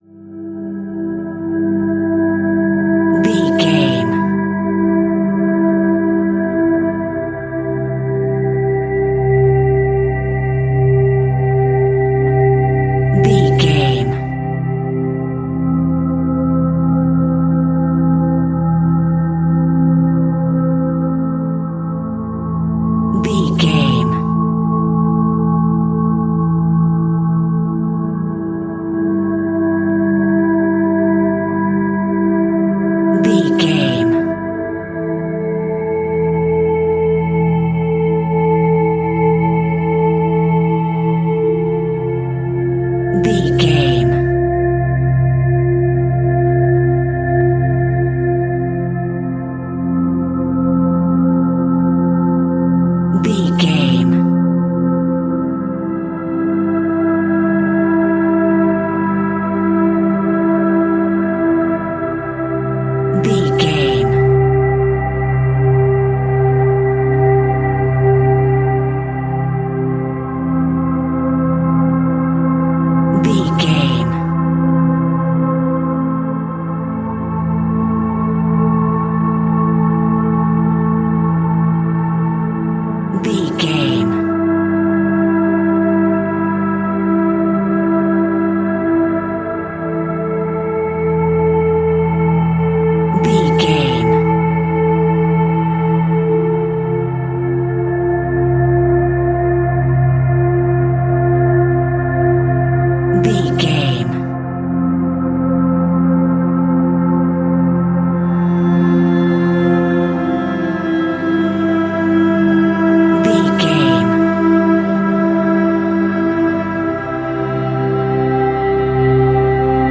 Aeolian/Minor
Slow
dreamy
meditative
quiet
tranquil
mystical
ethereal
hypnotic
cello
synthesiser
strings